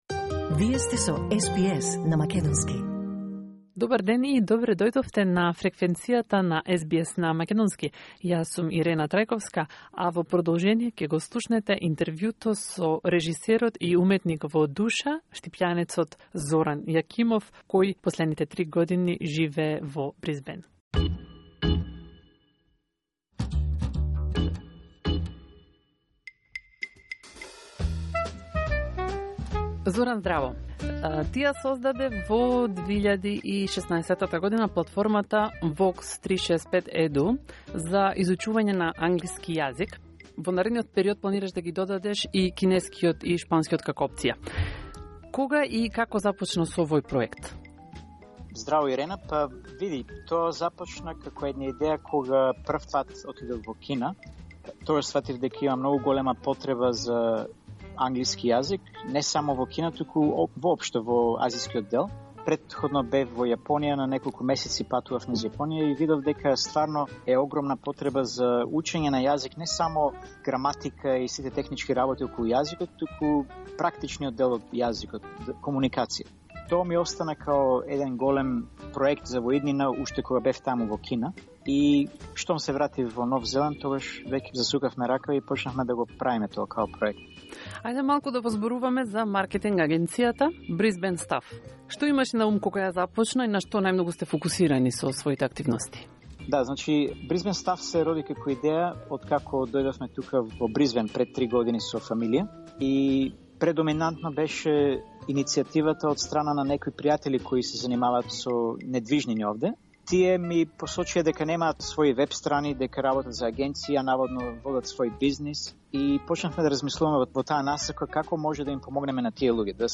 interview_final_for_podcast.mp3